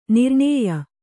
♪ nirṇēya